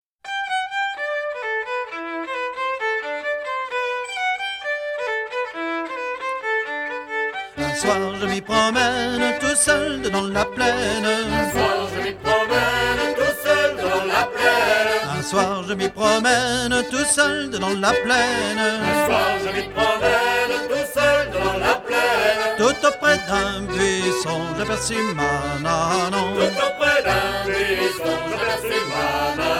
danse : ronde : grand'danse
Genre laisse
Pièce musicale éditée